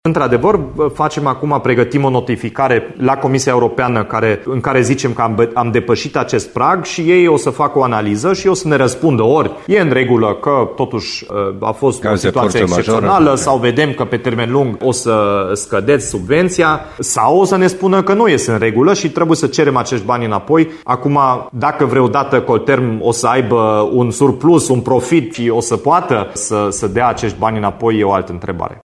Dominic Fritz a declarat, la Radio Timișoara, că primăria îi va cere Comisiei Europene să se pronunțe cu privire la sumele alocate Colterm:
Dominic-Fritz-Colterm.mp3